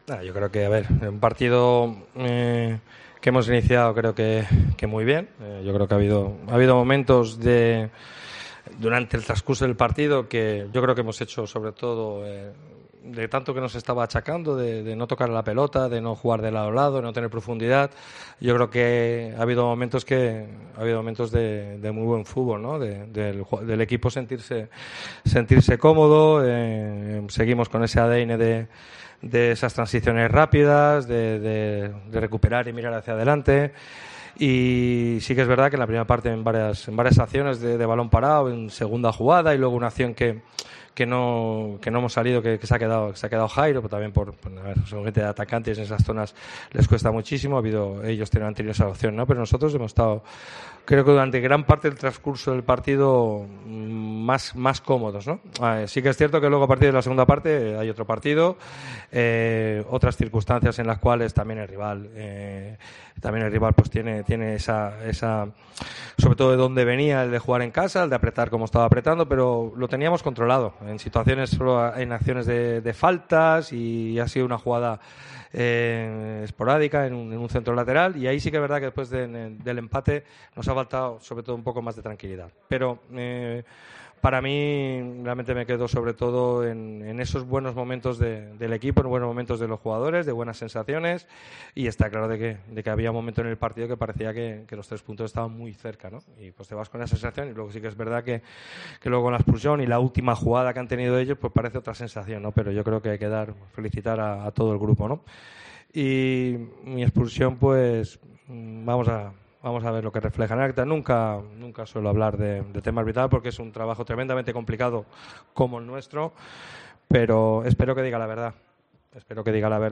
AUDIO: Escucha aquí las palabras del míster del Málaga tras el empate 1-1 en El Toralín, ante la Deportiva Ponferradina